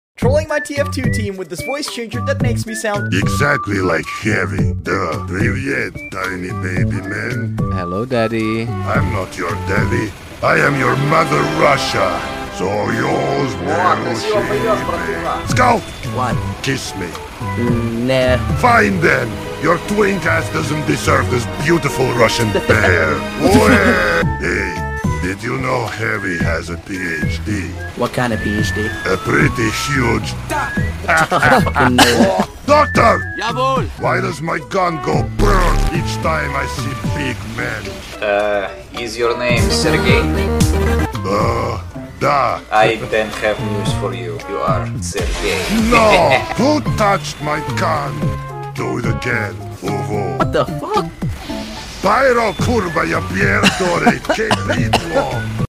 Heavy TF2 Voice Trolling sound effects free download